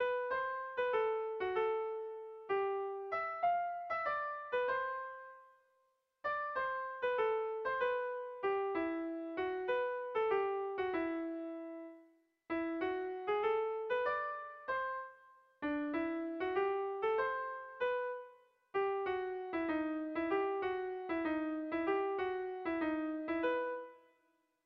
Sentimenduzkoa
Zortziko txikia (hg) / Lau puntuko txikia (ip)
ABDE